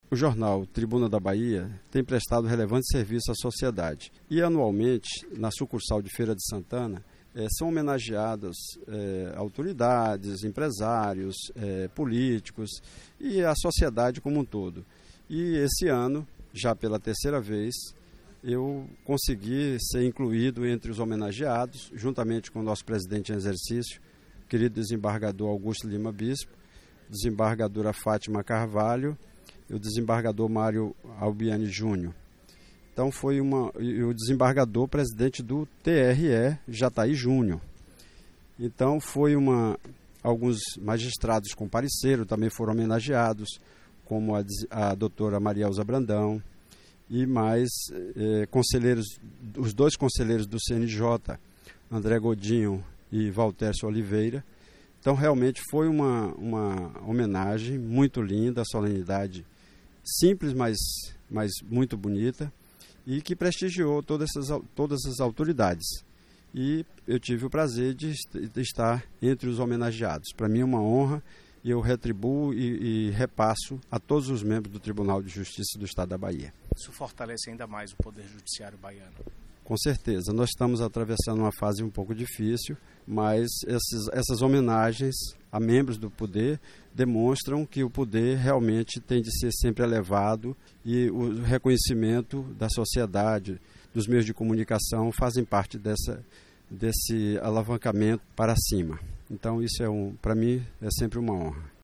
A solenidade ocorreu no Casarão Fróes da Mota, na cidade de Feira de Santana, no dia 12/12, e marcou o encerramento das comemorações pelos 50 anos do Jornal Tribuna da Bahia.
O Desembargador Baltazar Miranda falou sobre a homenagem e sobre o orgulho de estar, pela terceira vez, entre os escolhidos para receber o troféu.